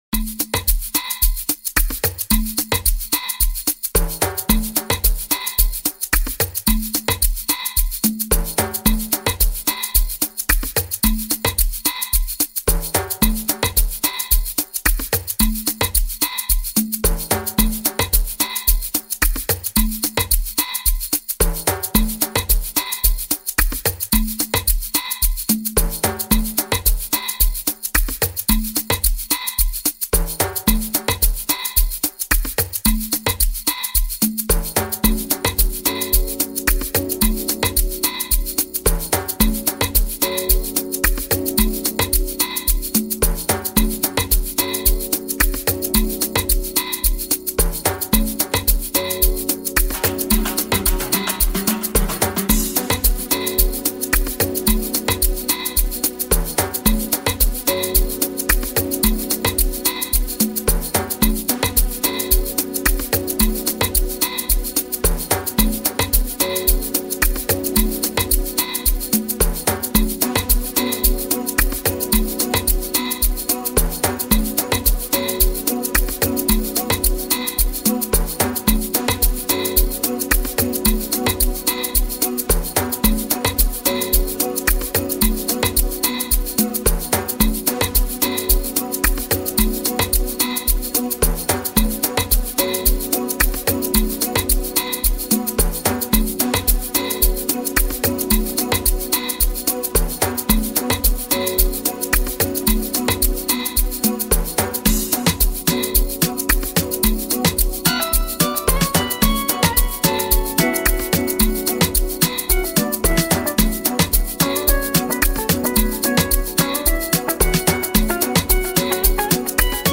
hits very hard
Private School Amapiano